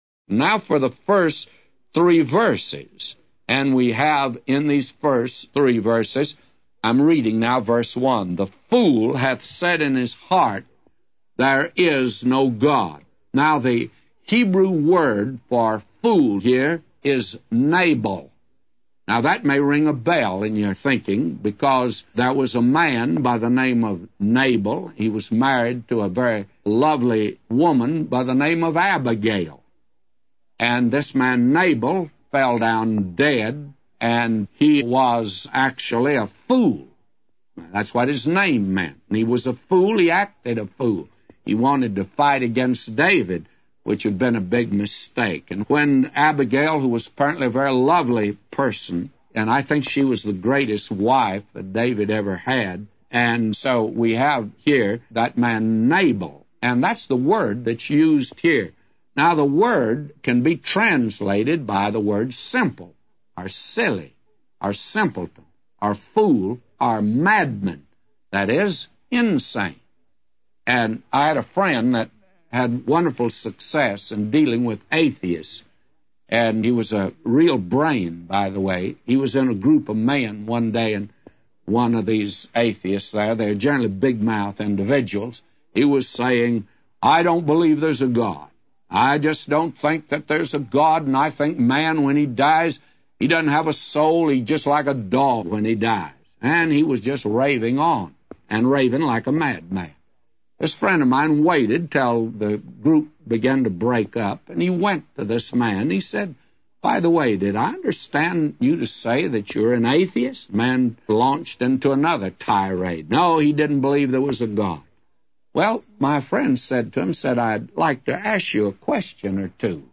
A Commentary